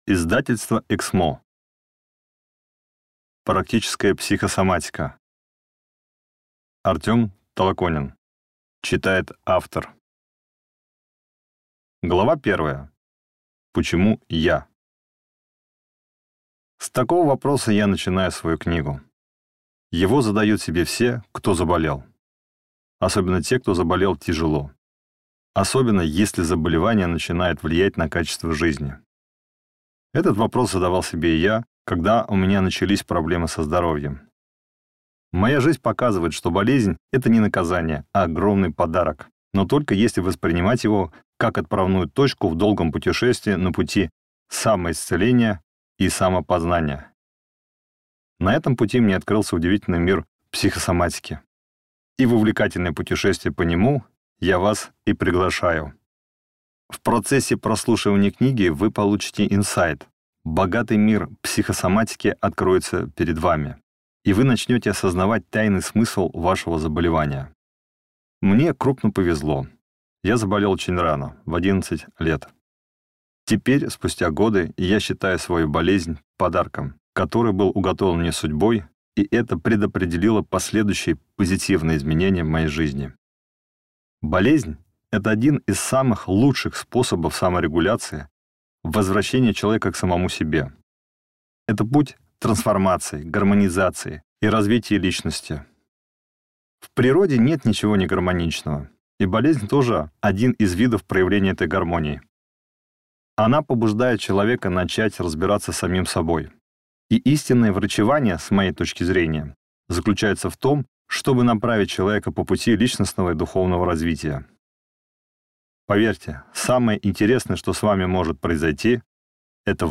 Аудиокнига Практическая психосоматика. Какие эмоции и мысли программируют болезнь и как обрести здоровье | Библиотека аудиокниг